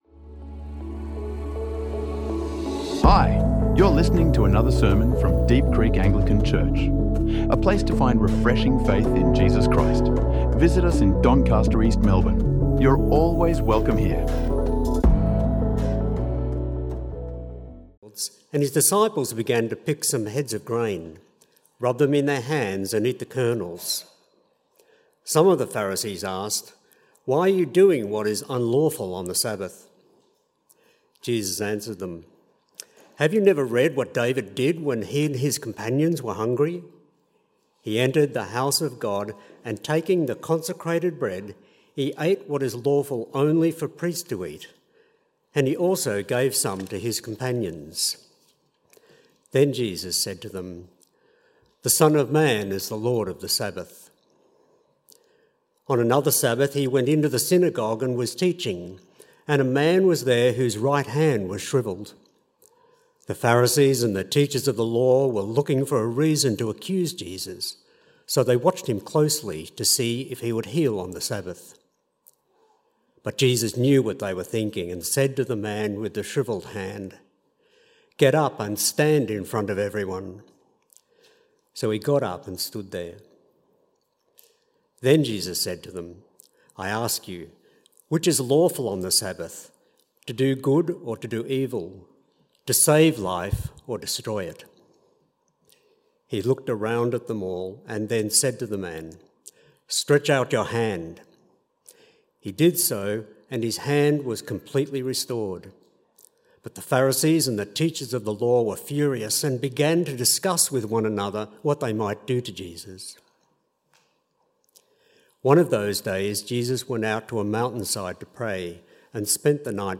This message was delivered as part of our special service for baptisms, confirmations and receptions into the church. Today, we reflect on the importance of taking this step in faith and what it means to live as Christ's ambassadors in the world. Centred on two encounters from Jesus' ministry, the message challenges us to see the Sabbath not as a burden, but as a gift of restoration, hope and joy.